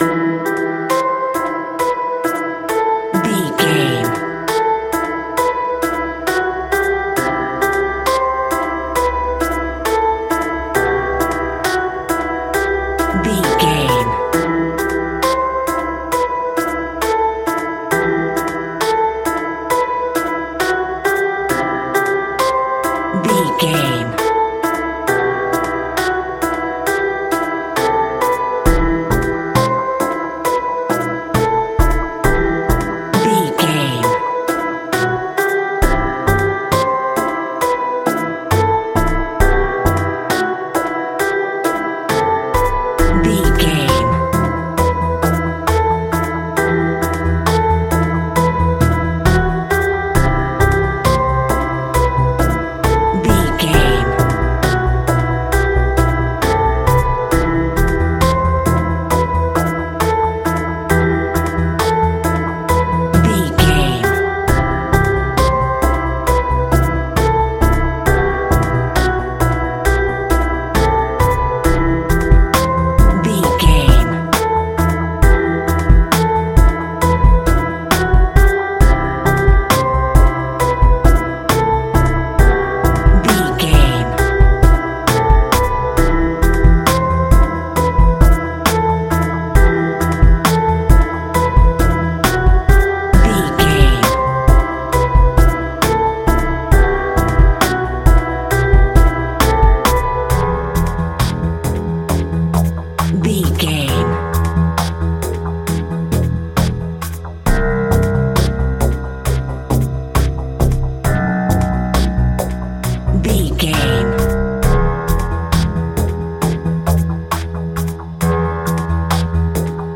Aeolian/Minor
scary
ominous
dark
suspense
haunting
eerie
synthesizer
percussion
Synth Pads
Synth Strings
synth bass